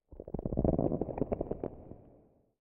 Minecraft Version Minecraft Version 1.21.5 Latest Release | Latest Snapshot 1.21.5 / assets / minecraft / sounds / ambient / nether / nether_wastes / ground4.ogg Compare With Compare With Latest Release | Latest Snapshot